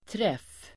Uttal: [tref:]